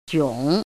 怎么读
jiǒng
jiong3.mp3